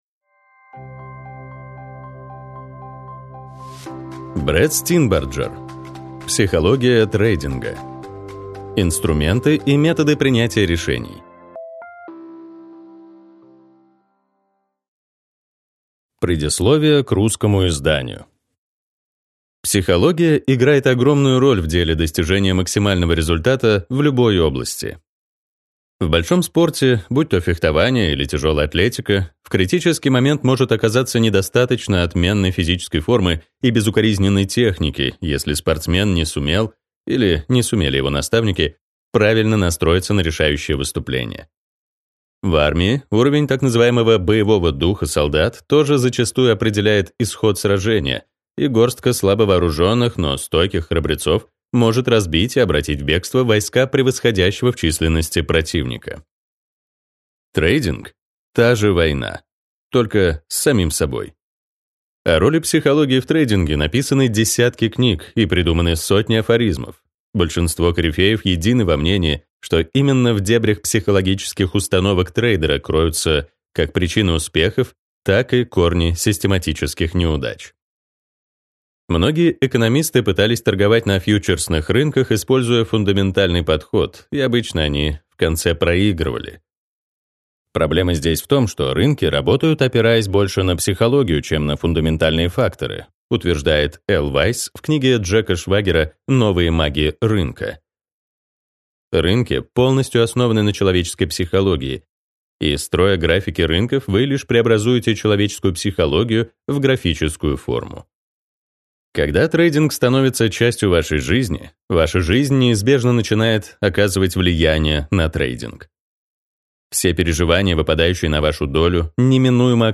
Аудиокнига Психология трейдинга. Инструменты и методы принятия решений | Библиотека аудиокниг